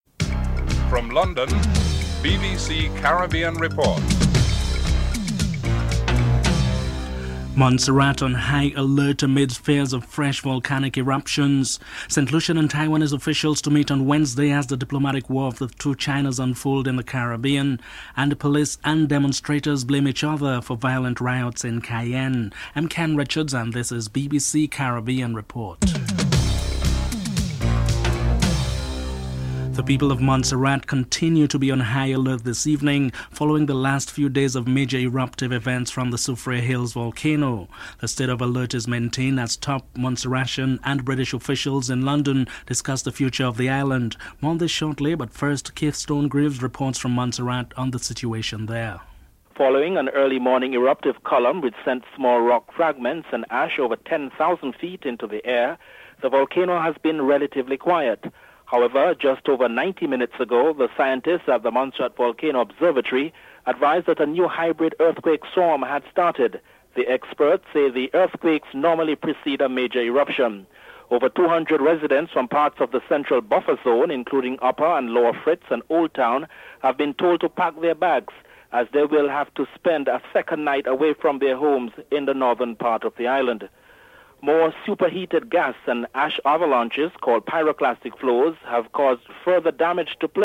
The British Broadcasting Corporation
1. Headlines (00:00-29)